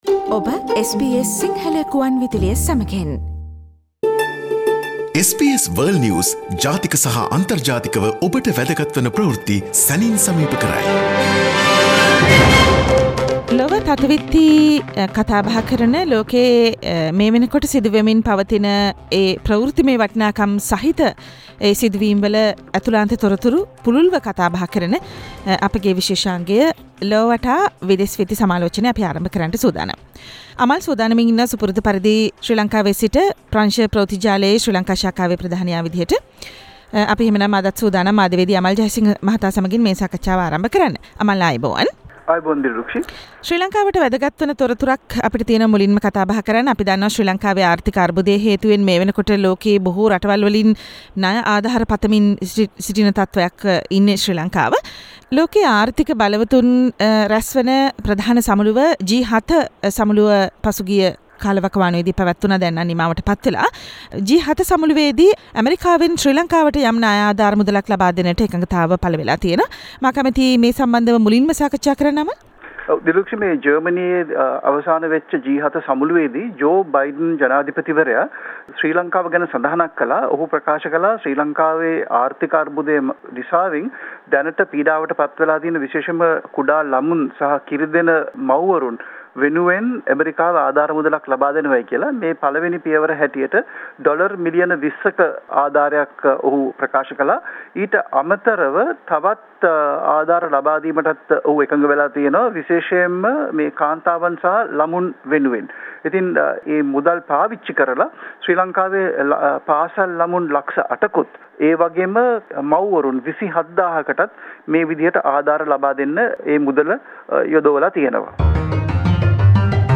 and the world news critic World's prominent news highlights in a few minutes - listen to SBS Si Sinhala Radio's weekly world News wrap on Friday Share